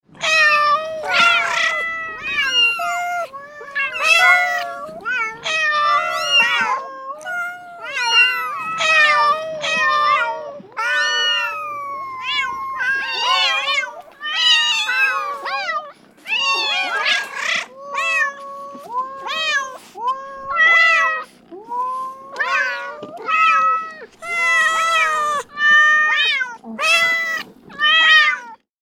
دانلود صدای میو میو کردن چند گربه با هم از ساعد نیوز با لینک مستقیم و کیفیت بالا
جلوه های صوتی